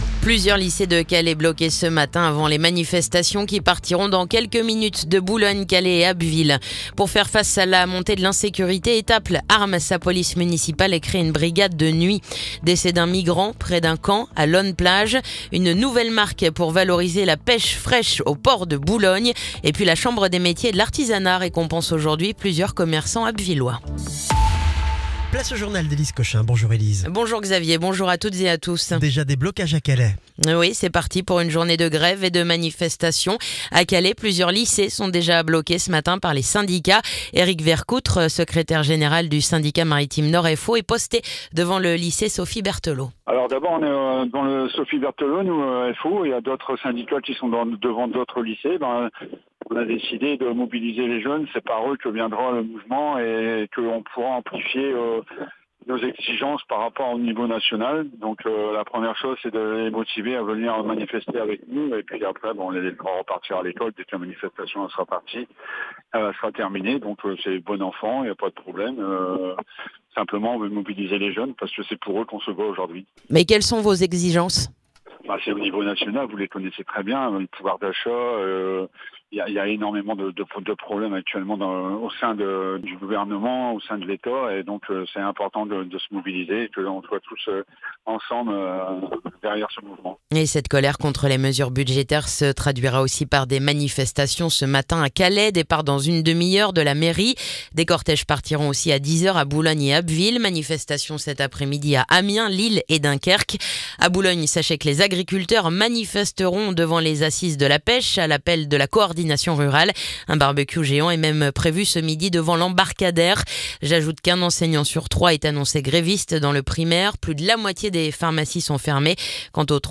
Le journal du jeudi 18 septembre